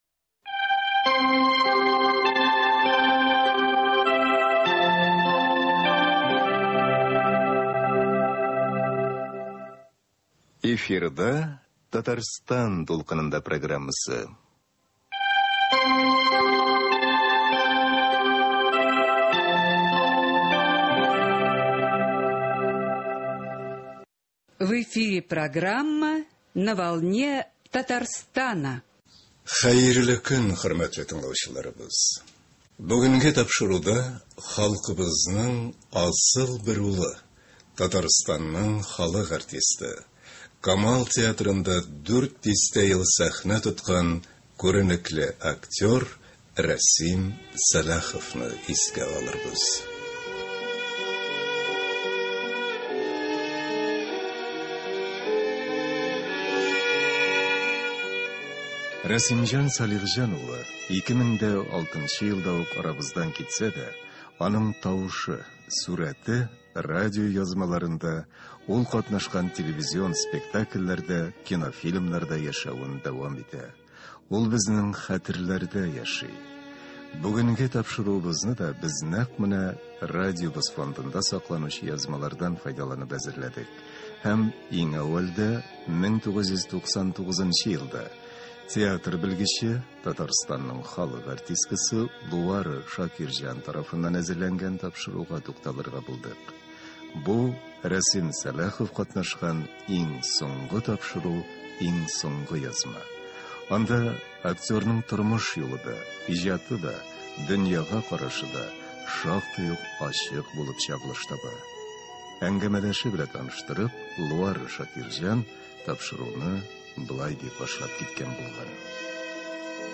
артистның юбилее уңаеннан әзерләнгән әңгәмә нигез итеп алынды
спектакльләрдән өзекләр һәм аның гармунда уйнавы бирелә